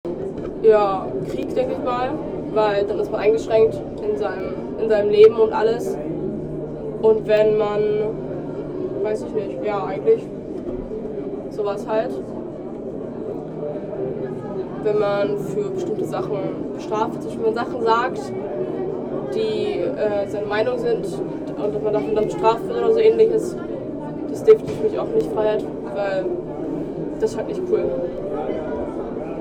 Standort der Erzählbox:
Stendal 89/90 @ Stendal